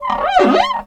slapstickWipe.ogg